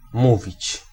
Ääntäminen
IPA: /ˈmu.vit͡ɕ/